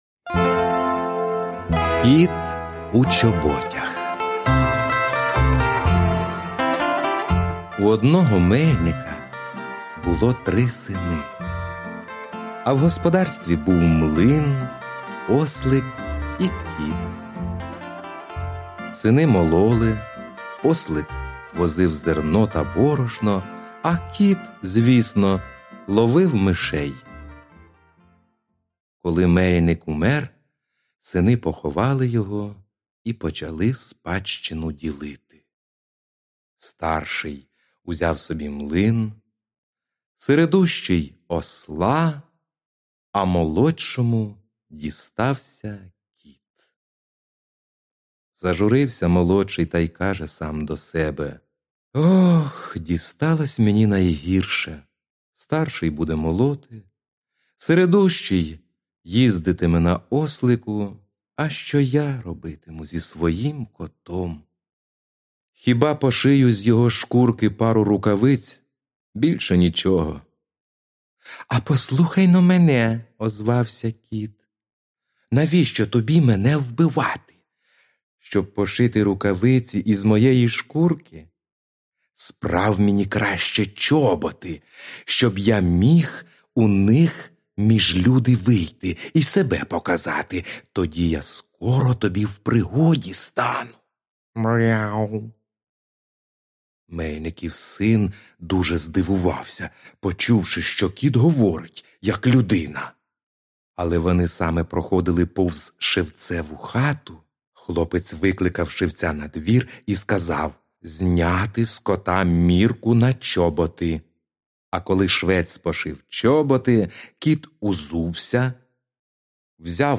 Аудіоказка Кіт у чоботях